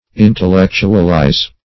Intellectualize \In`tel*lec"tu*al*ize\, v. t.